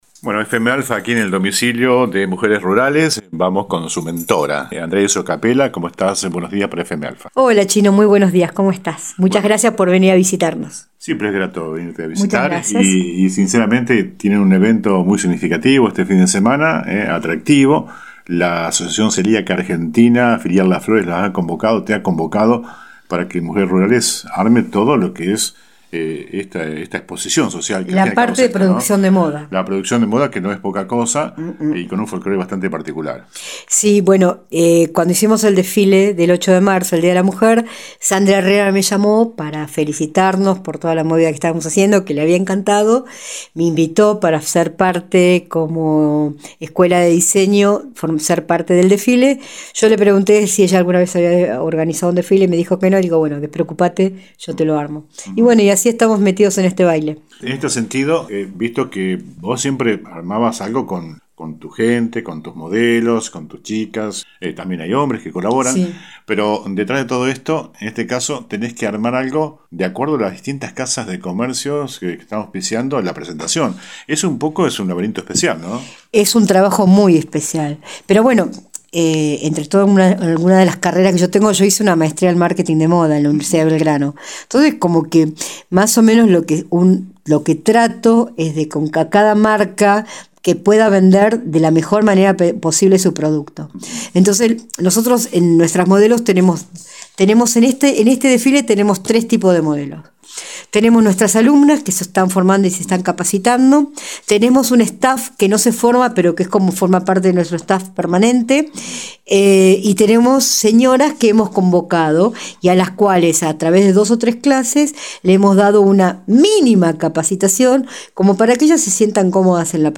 Al respecto, la 91.5 habló este jueves con la reconocida diseñadora quien contó los preparativos para el gran evento.